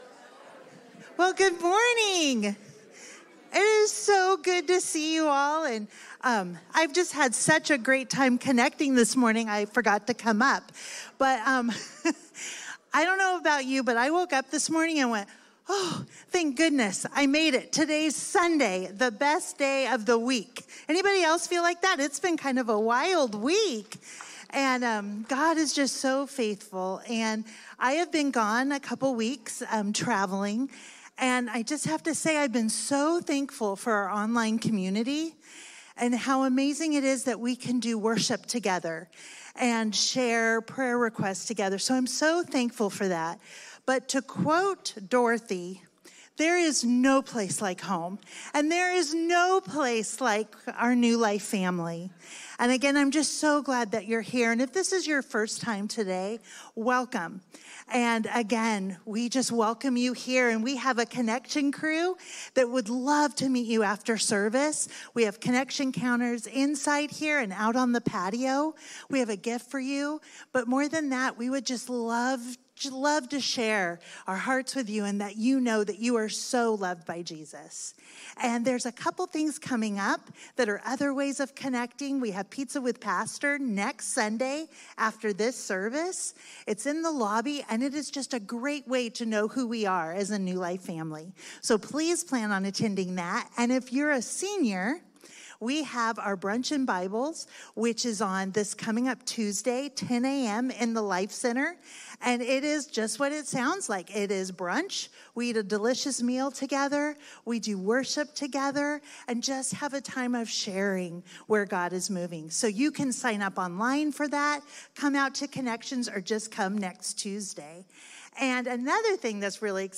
A message from the series "Romans 8."